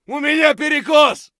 gun_jam_6.ogg